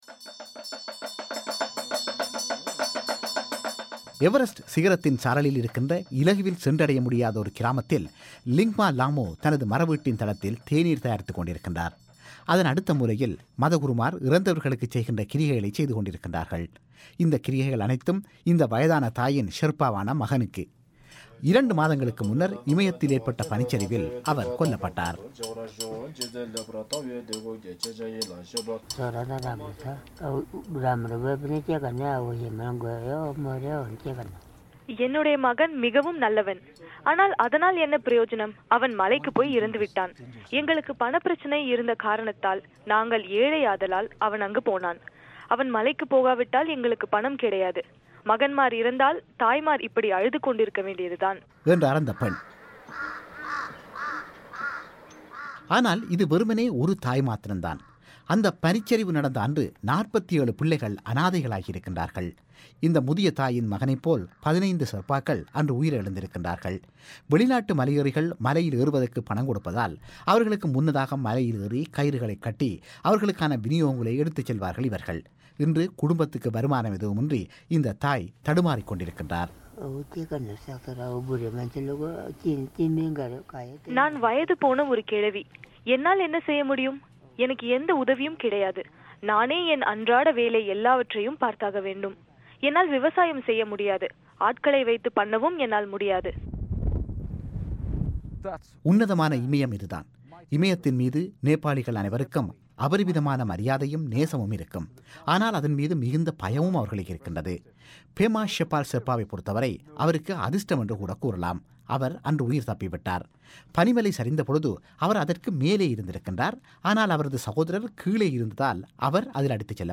செய்திக் குறிப்பு.